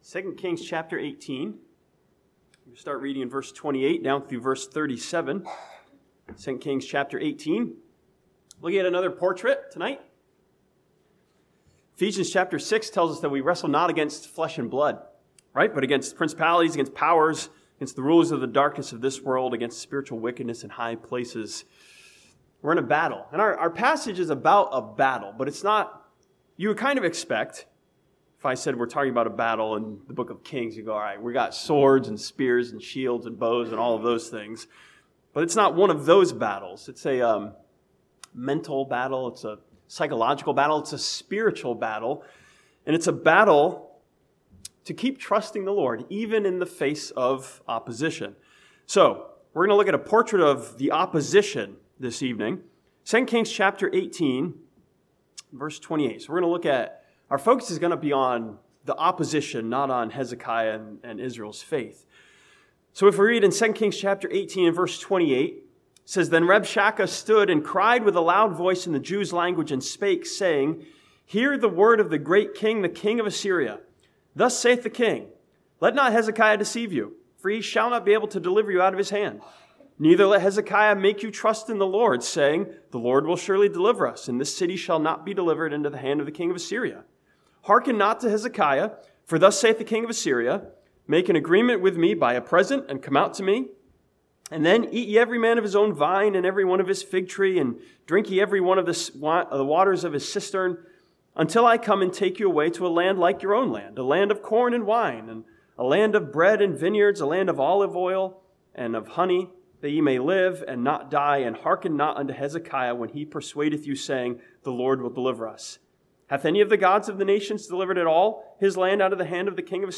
This sermon from 2 Kings 18 looks at a portrait of the opposition that tells us to give up and quit trusting in God.